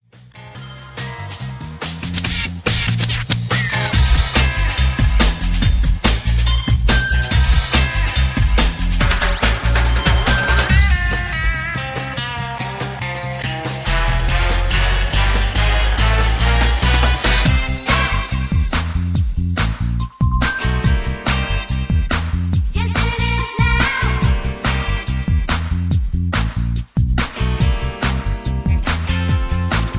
hip hop, r and b, top 40, backing tracks